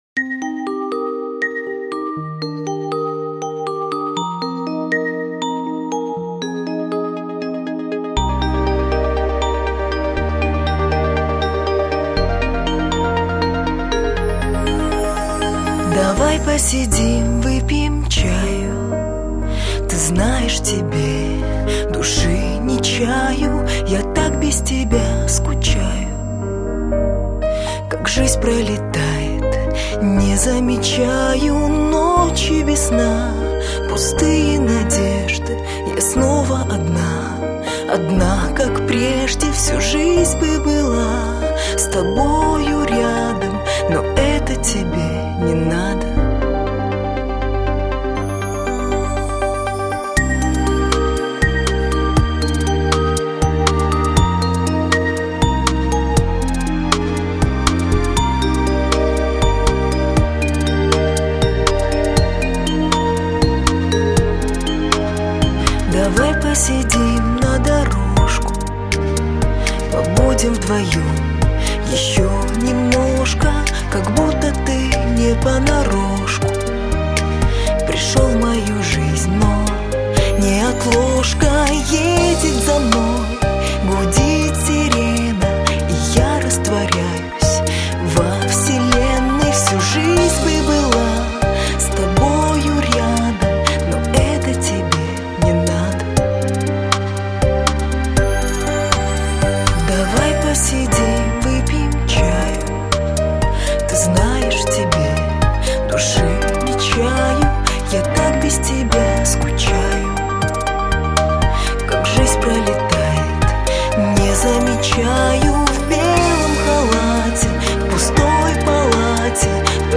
Жанр: Pop